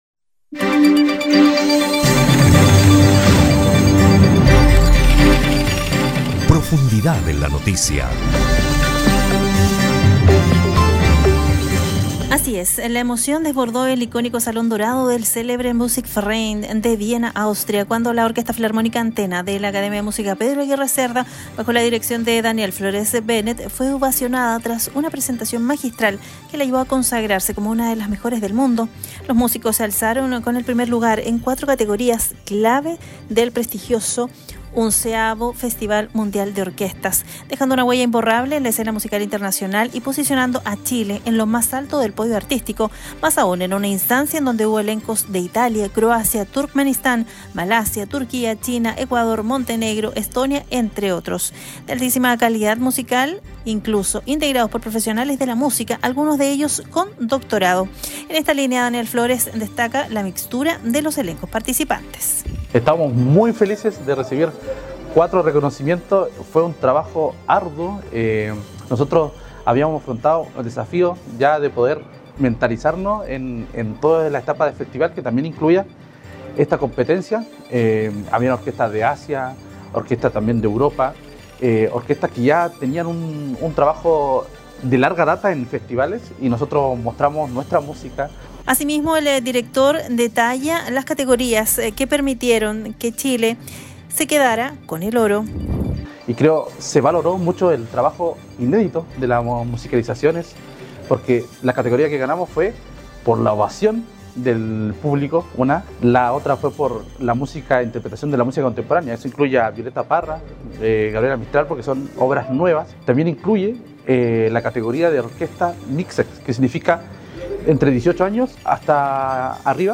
DESPACHO-ORO-FESTIVAL-CON-CORTINA.mp3